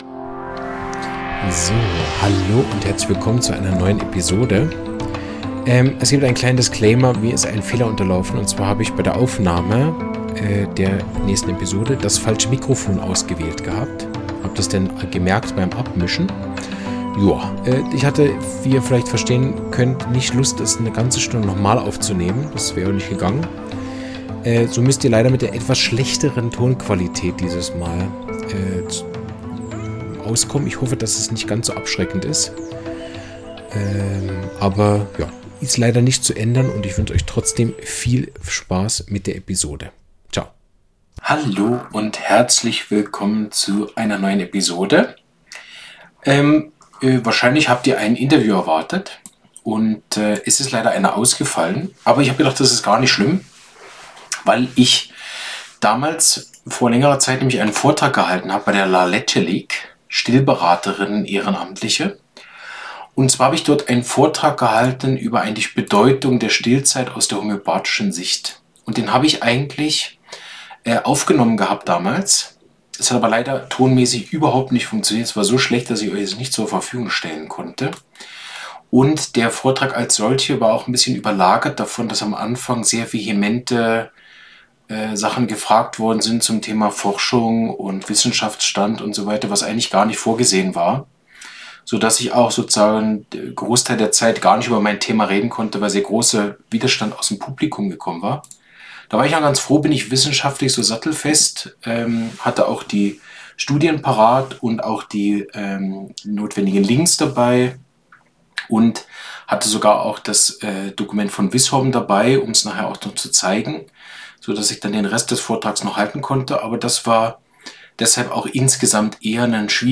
Lass dich inspirieren von einem Vortrag voller Herz, Forschung und lebendiger Praxis – für alle, die Stillen, Bi